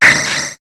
Cri d'Anorith dans Pokémon HOME.